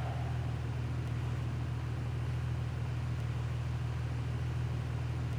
Index of /server/sound/vehicles/lwcars/lam_reventon
idle.wav